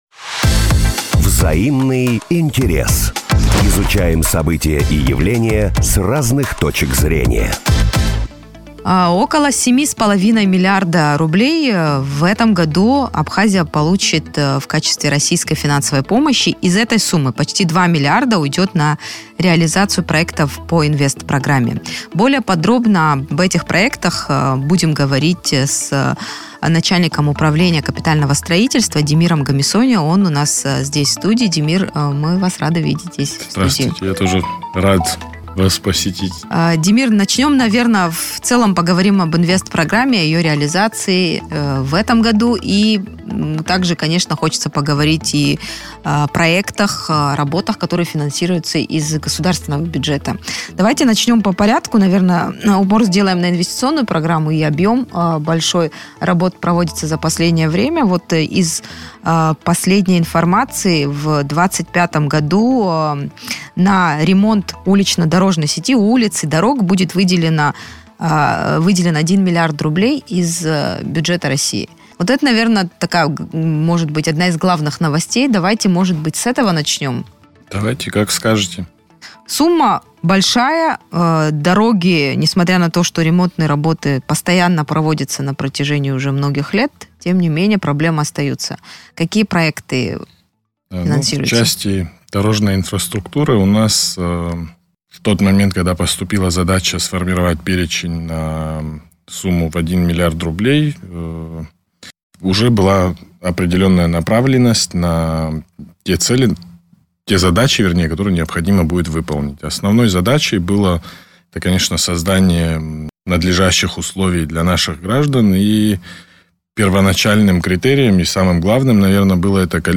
Реализация проектов по российской Инвестпрограмме в Абхазии. Интервью с главой УКС